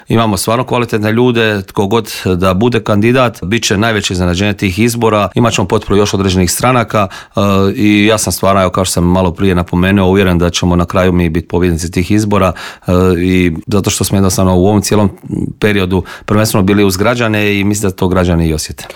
O ovogodišnjoj obljetnici i brojnim drugim političkim aktualnostima u Intervjuu Media servisa razgovarali smo s predsjednikom Hrvatskih suverenista Marijanom Pavličekom koji je poručio: "Čovjek se naježi kada vidi sve te mlade ljude koji idu prema Vukovaru."